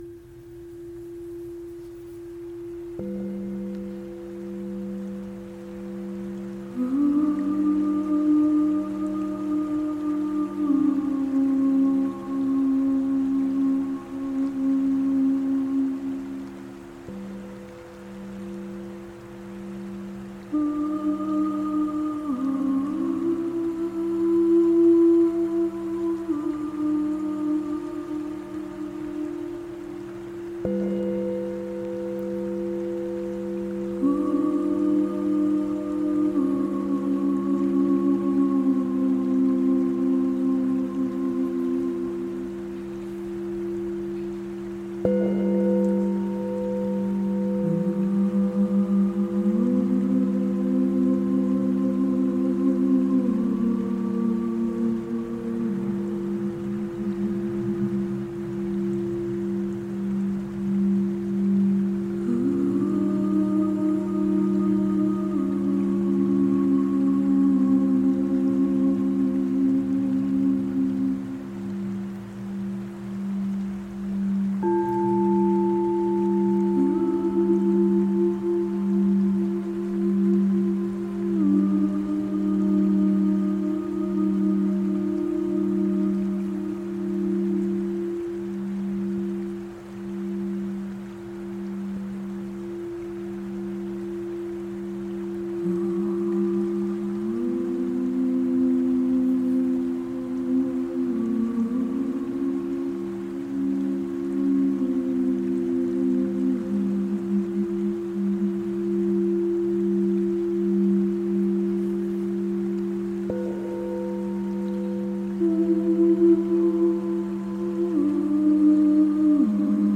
Mit dieser Klangreise lade ich dich ein, für ein paar wertvolle Minuten auszuruhen und Kraft zu tanken.
Klangschalen & Gesang
river_in_Iceland_final_mit_hall.mp3